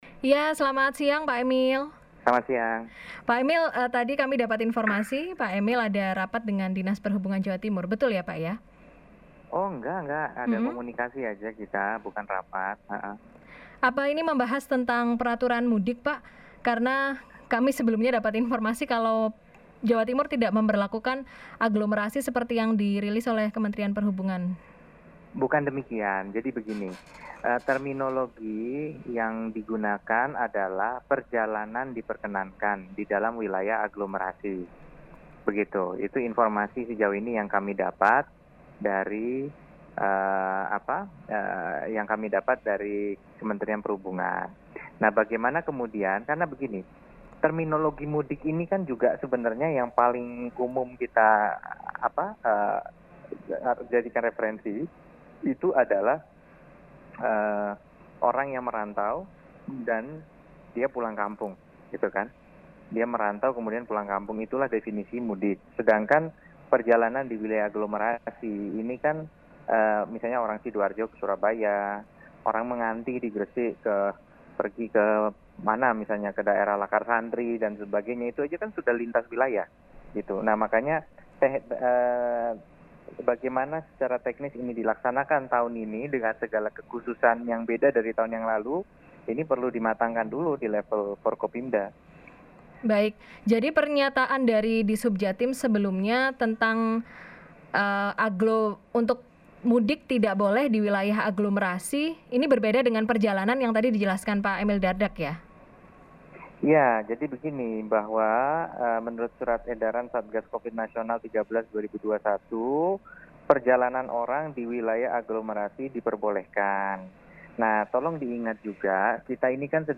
“Mudik itu untuk orang yang merantau dan pulang kampung. Sedangkan perjalanan di wilayah aglomerasi ini misalnya orang Sidoarjo ke Surabaya, perjalanan lintas wilayah,” kata Emil kepada Radio Suara Surabaya, Jumat (16/4/2021).
Berikut penjelasan lengkap Emil Dardak Wakil Gubernur Jawa Timur:
INTERVIEW.mp3